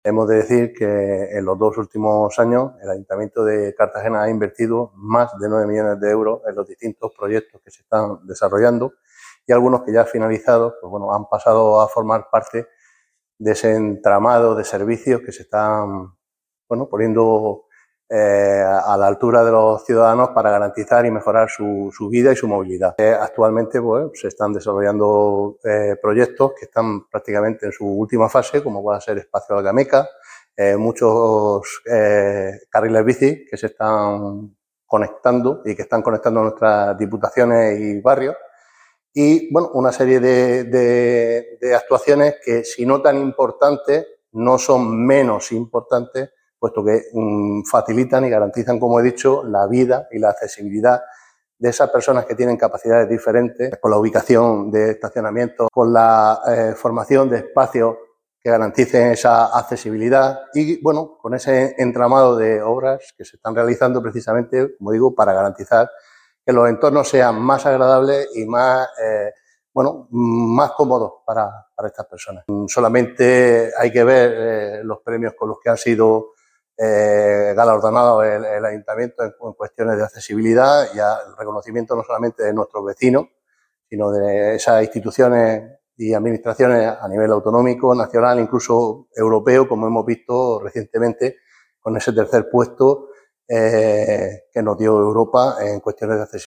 Enlace a Declaraciones del Concejal de Seguridad Ciudadana sobre la Mesa de Movilidad